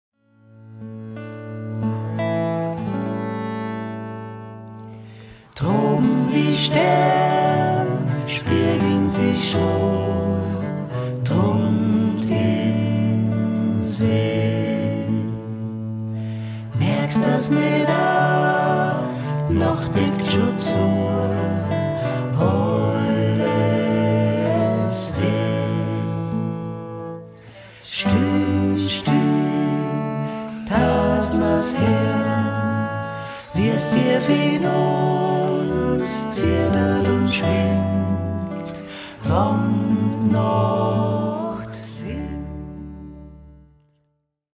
guitar, bass, ukulele, vocals, arrangements
viola da gamba, bass
akkordion, vocals